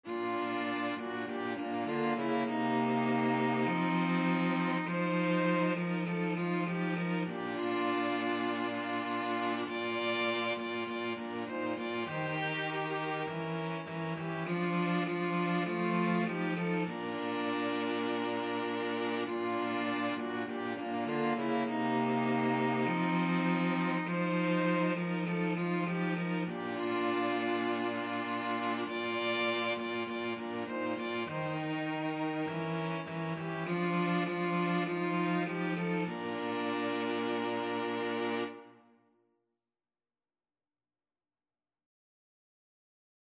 ViolinViolaCello
Bb major (Sounding Pitch) (View more Bb major Music for String trio )
4/4 (View more 4/4 Music)
String trio  (View more Easy String trio Music)
Traditional (View more Traditional String trio Music)